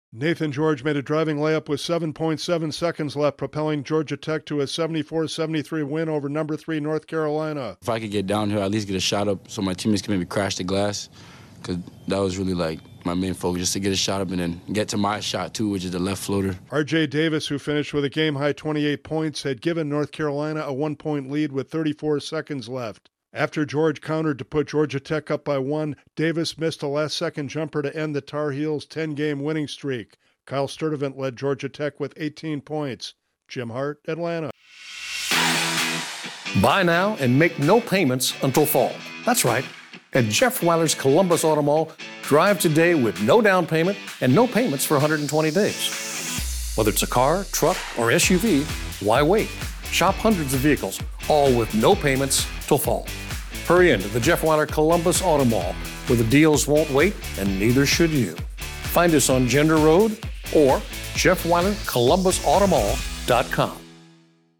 Georgia Tech ends North Carolina's 10-game winning streak. Correspondent